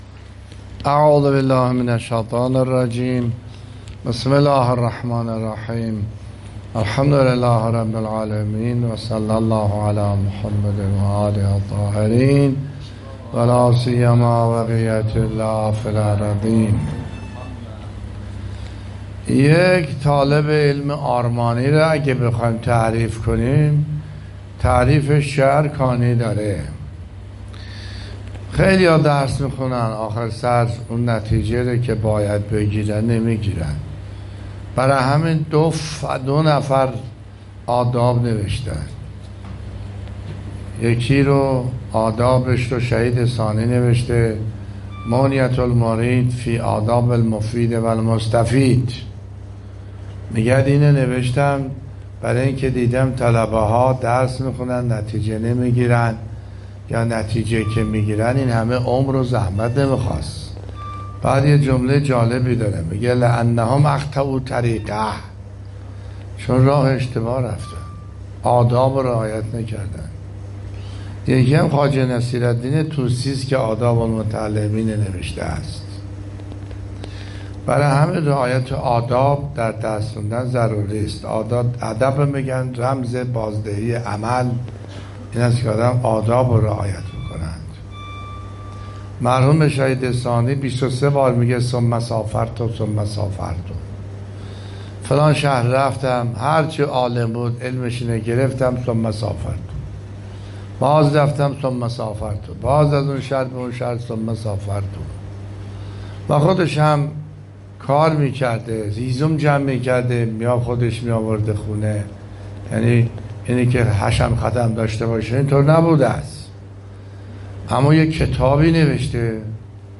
درس اخلاق | آیا از زندگی طلبگی خود راضی هستید؟ + صوت